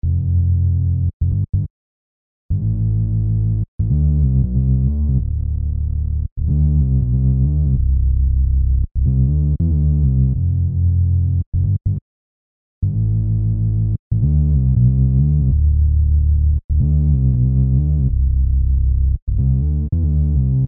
描述：果味循环
标签： 93 bpm Hip Hop Loops Bass Loops 3.47 MB wav Key : Unknown
声道立体声